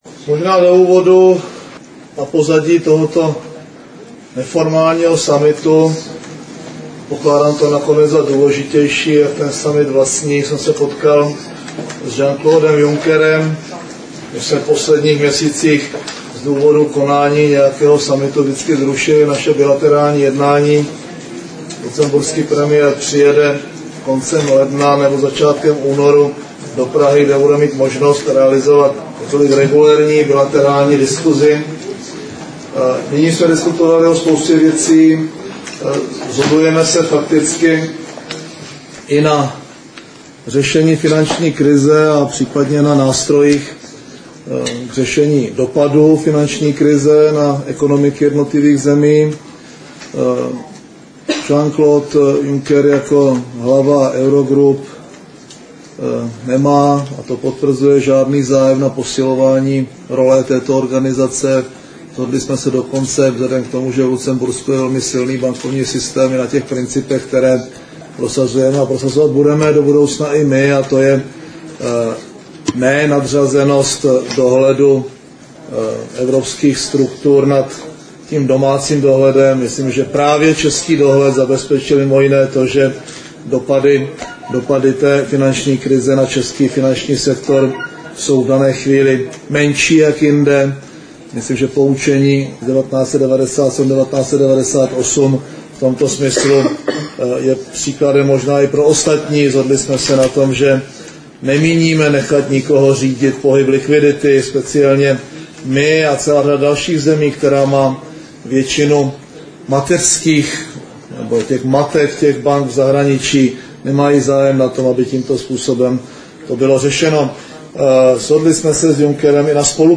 Zpráva nabízí také audiozáznam tiskové konference české delegace v čele s předsedou vlády Mirkem Topolánkem.
Premiér Mirek Topolánek na tiskové konferenci v Bruselu
Zvukový záznam tiskové konference